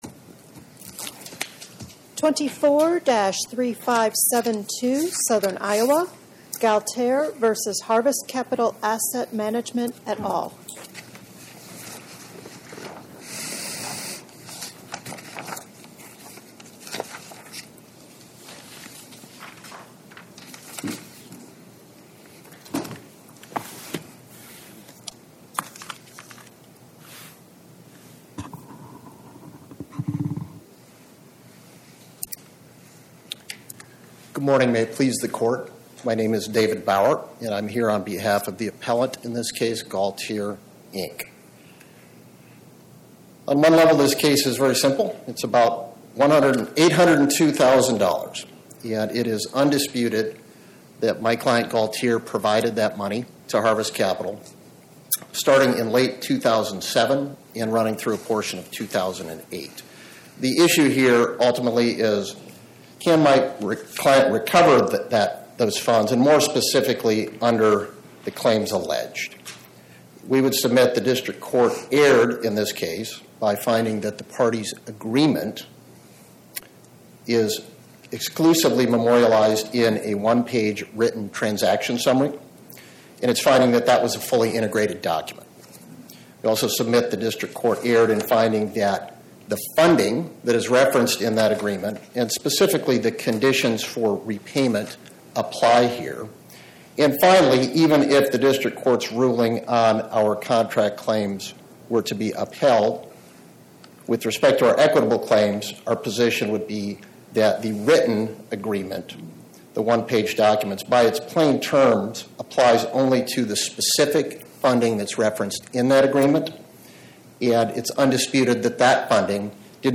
My Sentiment & Notes 24-3572: Galtere, Inc. vs Harvest Capital Asset Mgmt. Podcast: Oral Arguments from the Eighth Circuit U.S. Court of Appeals Published On: Wed Dec 17 2025 Description: Oral argument argued before the Eighth Circuit U.S. Court of Appeals on or about 12/17/2025